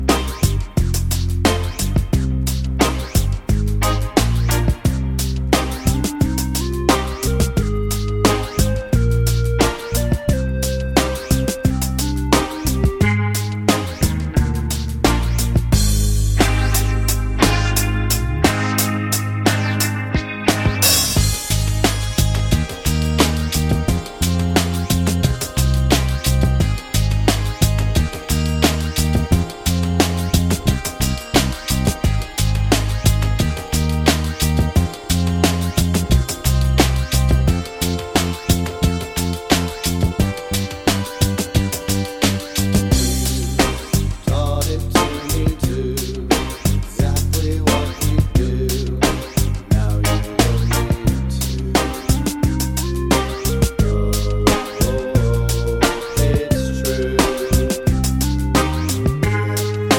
no Backing Vocals Indie / Alternative 4:03 Buy £1.50